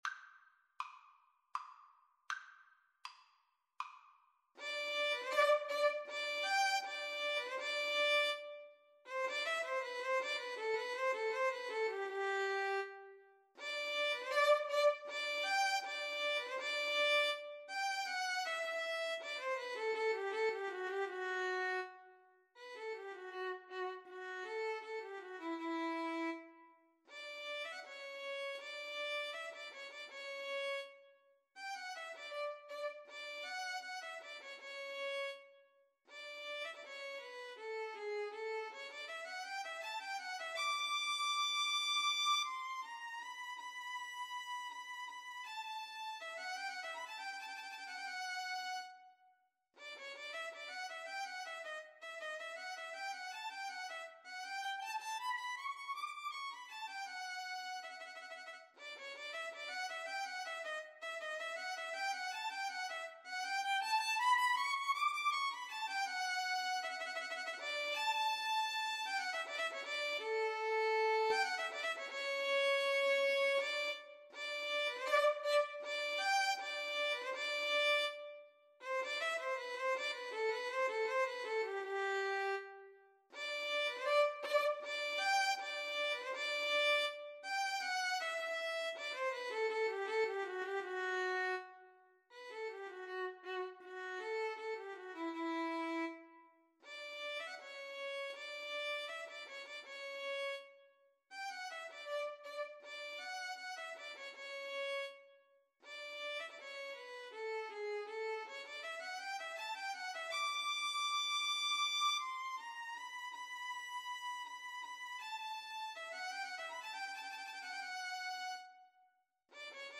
Free Sheet music for Violin Duet
G major (Sounding Pitch) (View more G major Music for Violin Duet )
Andante
3/4 (View more 3/4 Music)
Classical (View more Classical Violin Duet Music)